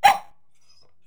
Bark2.wav